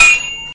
愚公移山" 缓慢切割
描述：为方便起见，录制了刀具声音。它们不是最干净的音频，但应该可以在紧要关头使用。
标签： 中世纪 手榴弹 斜线 战斗 战士 战斗 金属 黑客攻击 骑士 战争 动作缓慢 吓人
声道立体声